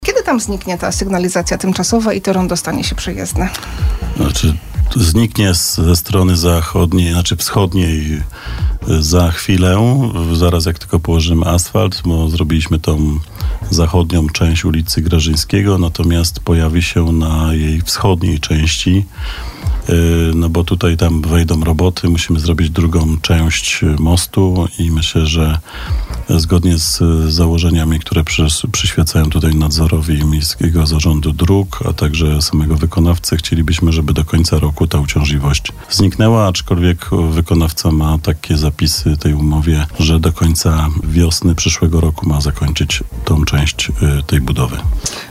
O kwestii tej rozmawialiśmy z Przemysławem Kamińskim, wiceprezydentem Bielska-Białej.